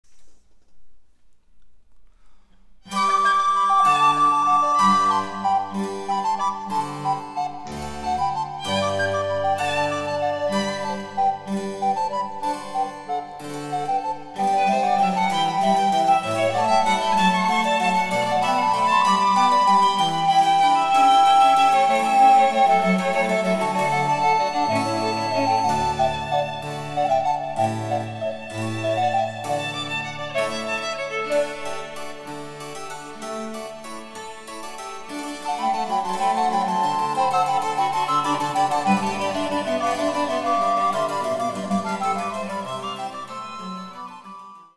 ・演奏例